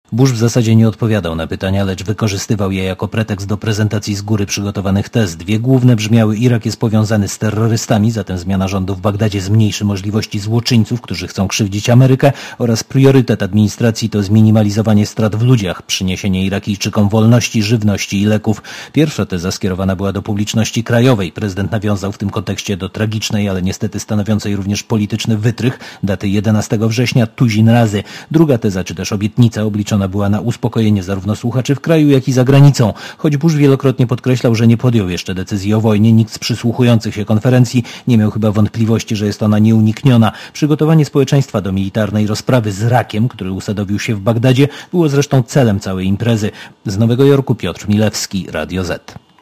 Relacja korespondenta Radia Zet z Nowego Jorku (386 KB)